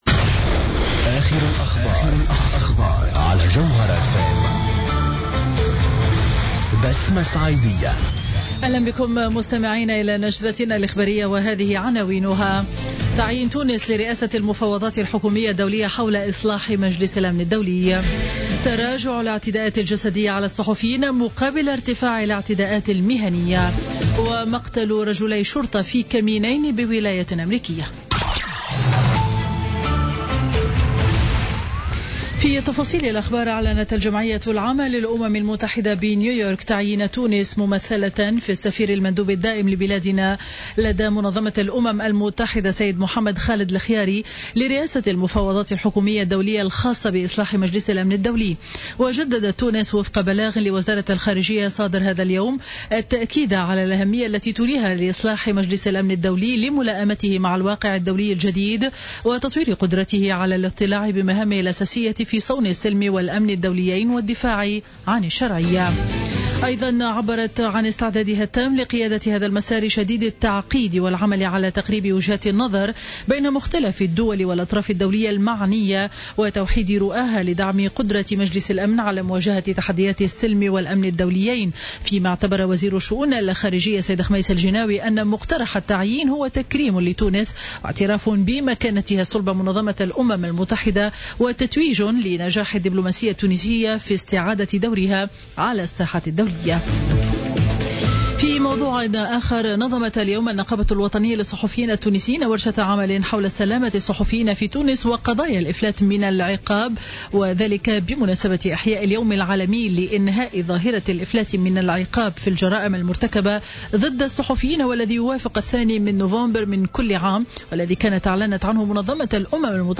Journal Info 12h00 du mercredi 2 novembre 2016